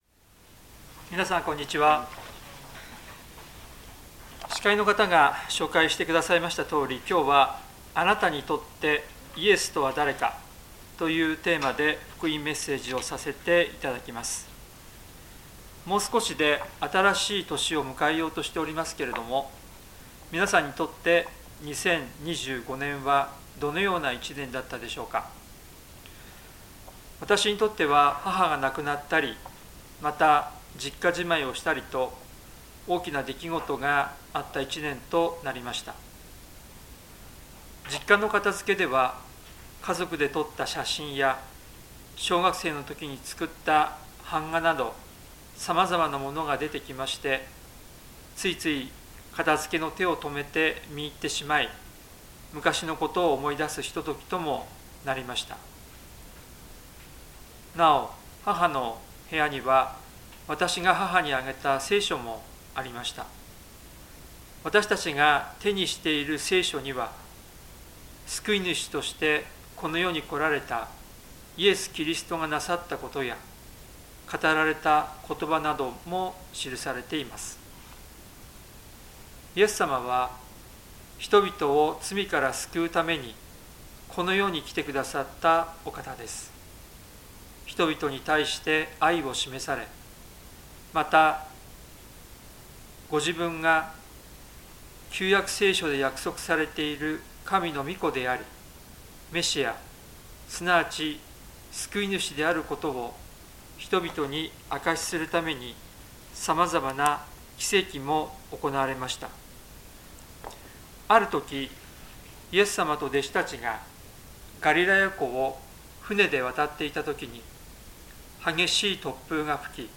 聖書メッセージ No.297